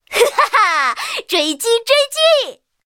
M4A3E2小飞象夜战语音.OGG